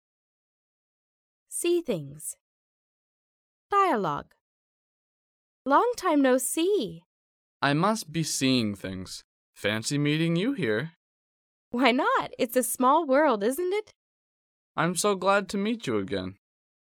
第一，迷你对话